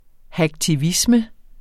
Udtale [ hagtiˈvismə ]